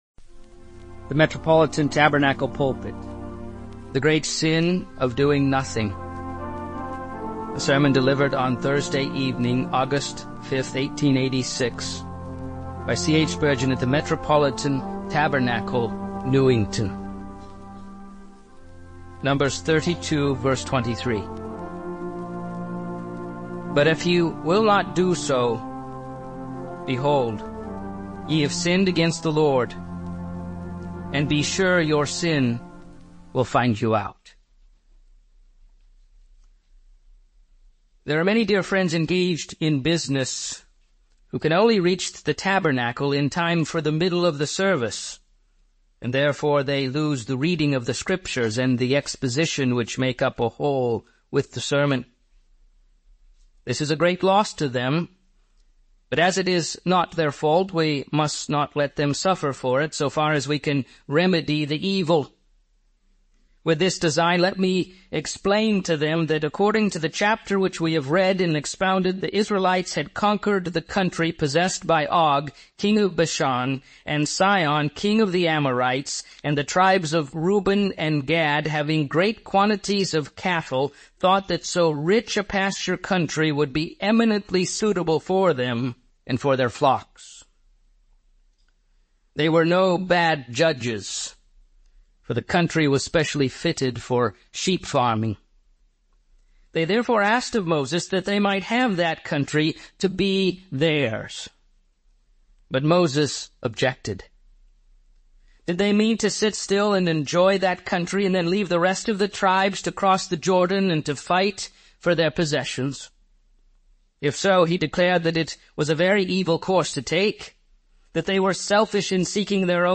-10s +10s Download Audio Sermon Notes